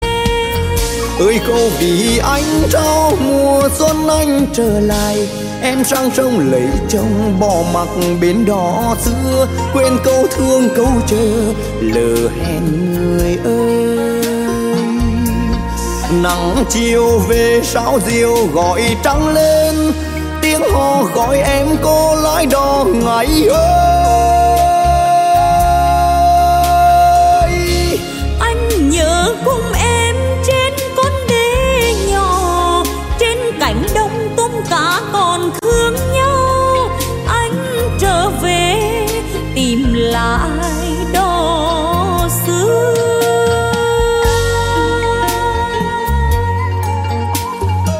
Nhạc Chuông Trữ Tình